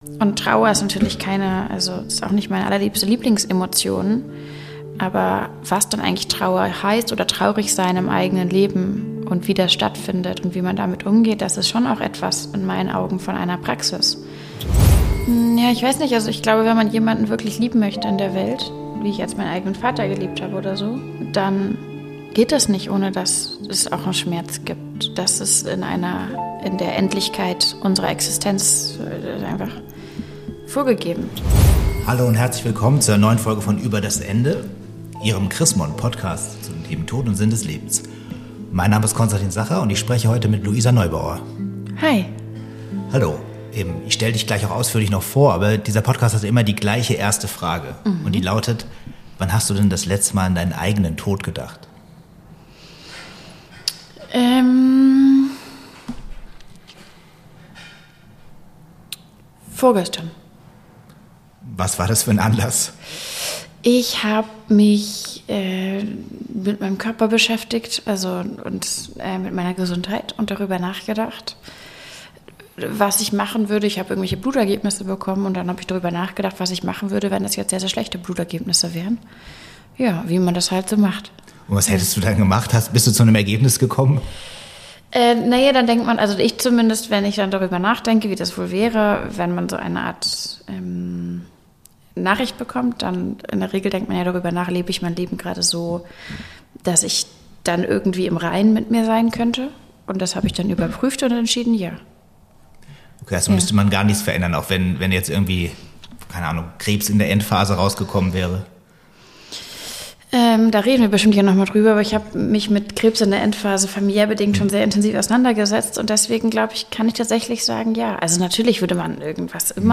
Im Gespräch geht es um den Zusammenhang von Glück und Schmerz, Mut für die Zukunft und die Frage, wie wir mit Ohnmacht umgehen.